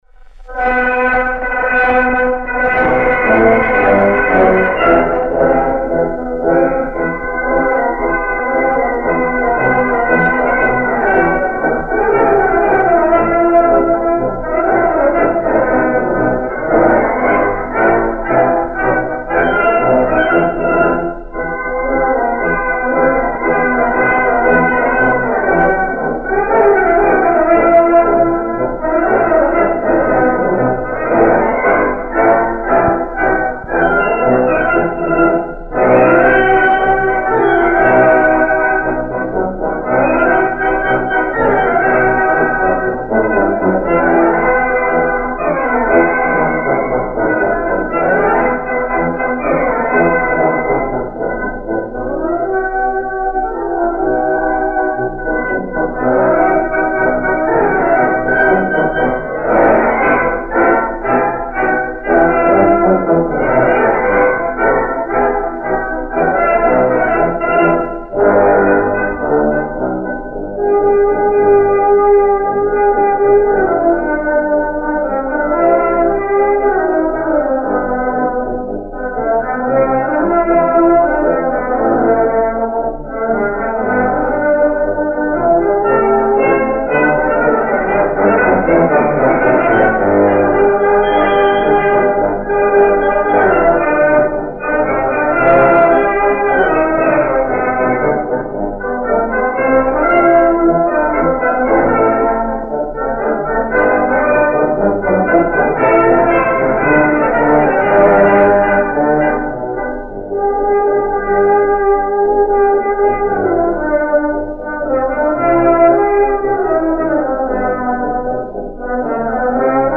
Марши
Одна из ранних записей